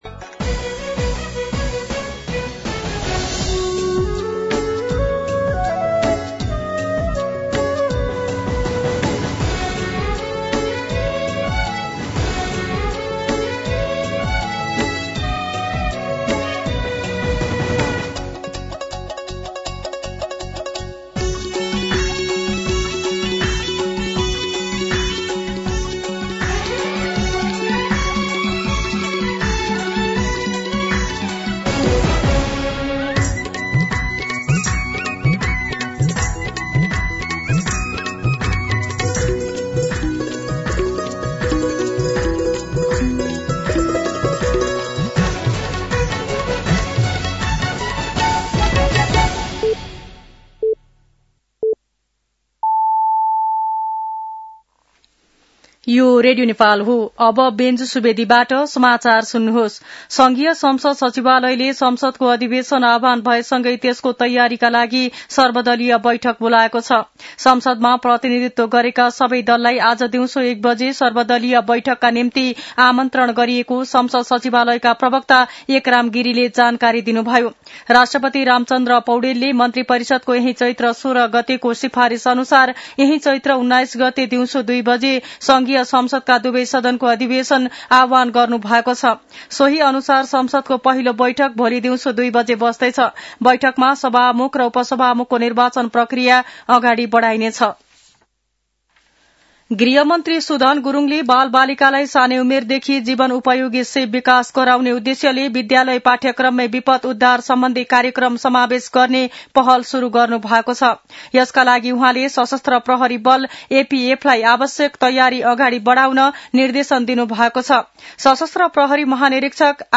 मध्यान्ह १२ बजेको नेपाली समाचार : १८ चैत , २०८२
12pm-News-18.mp3